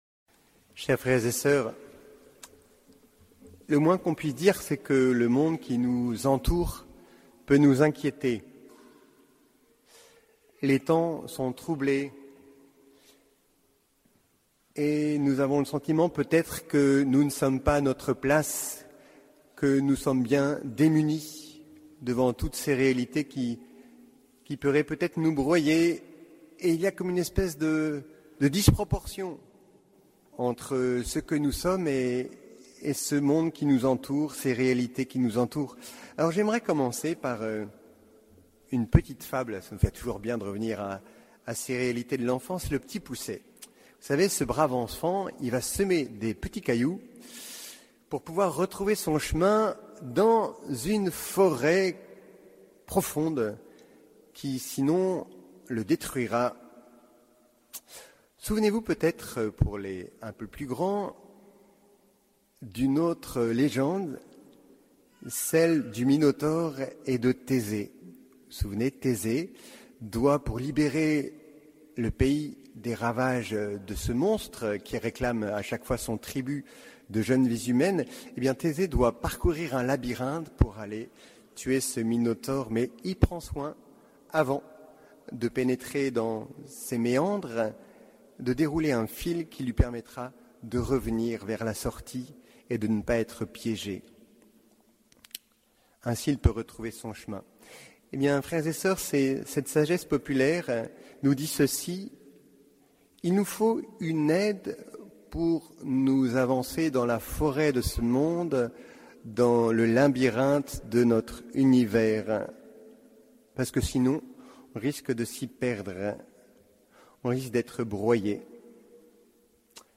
Homélie du 28e dimanche du Temps Ordinaire